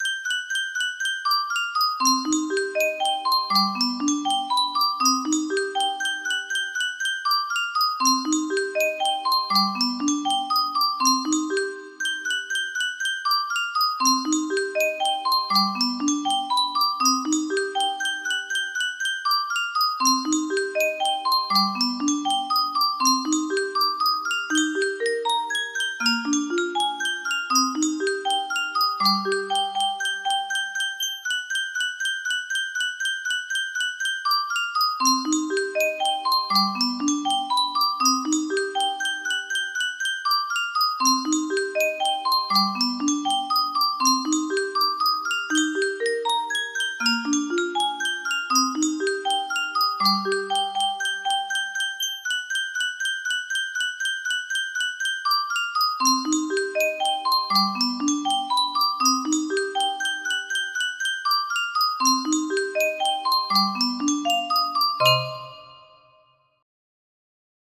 Fur elise music box melody
Full range 60